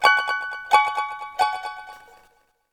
11127 triple appear strings blink
appear blink emerging game notification show-up strings triple sound effect free sound royalty free Sound Effects